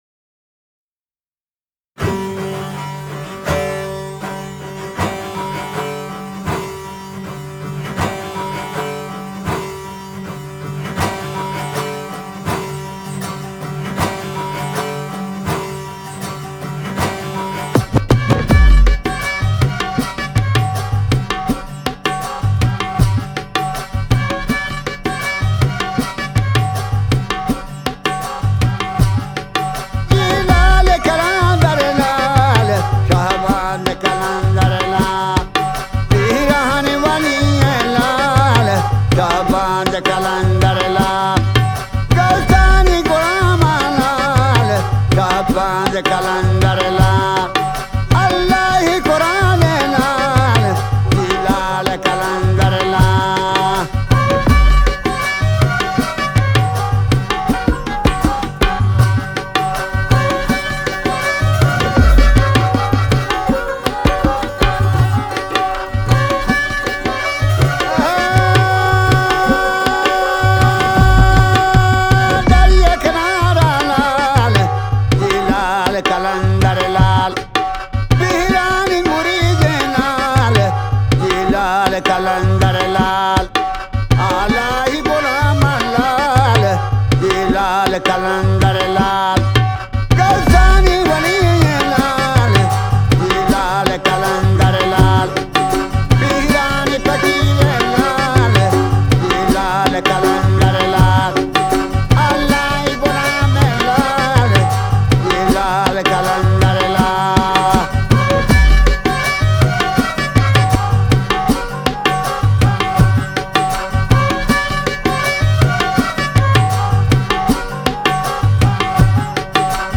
Genre : Alternative